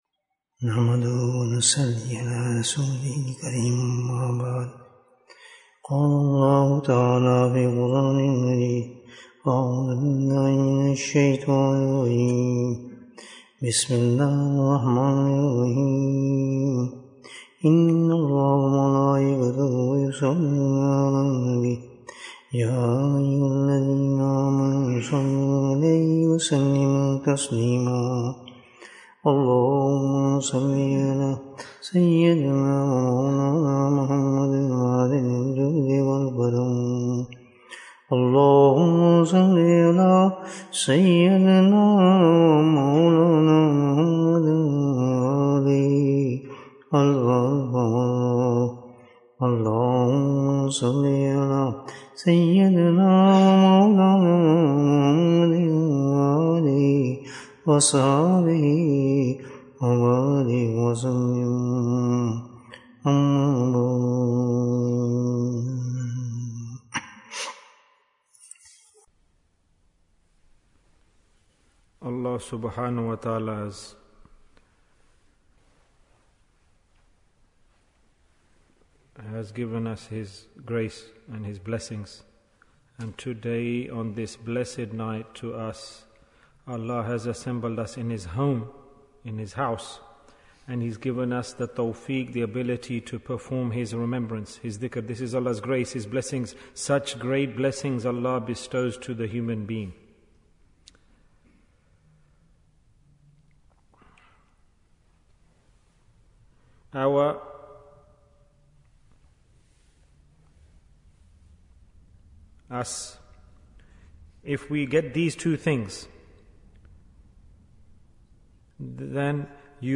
What is Deen? Bayan, 34 minutes4th May, 2023